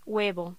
Locución: Huevo